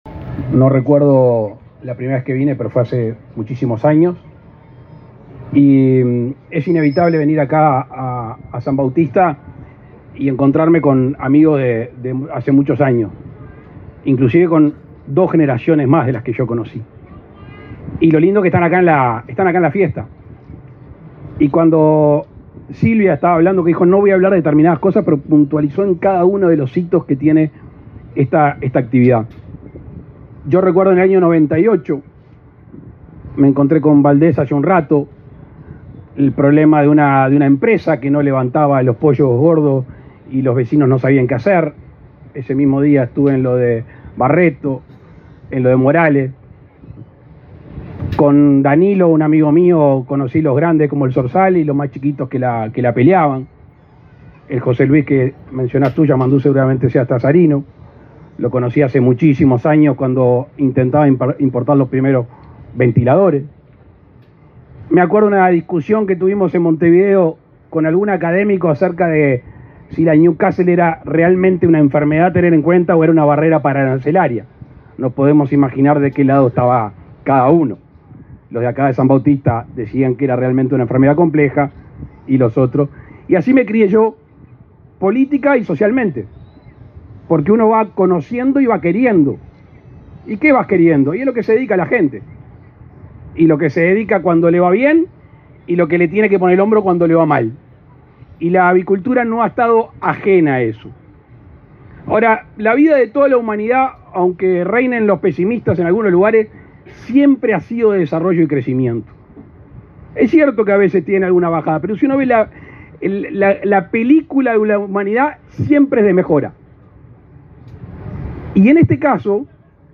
Palabras del presidente de la República, Luis Lacalle Pou
Palabras del presidente de la República, Luis Lacalle Pou 01/02/2024 Compartir Facebook X Copiar enlace WhatsApp LinkedIn Con la presencia del presidente de la República, Luis Lacalle Pou, se realizó, este 1.° de febrero, la 12.ª Edición de la Fiesta del Pollo y la Gallina, en Canelones.